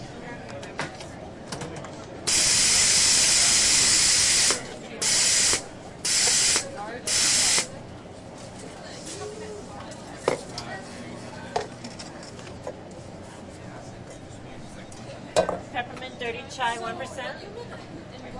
咖啡馆的声音 " 安静的咖啡馆
描述：咖啡师正在向公众开放咖啡店之前工作。 麦克风：在XY 90°设置中放大H4N Pro。
Tag: 大气 电子 咖啡店 氛围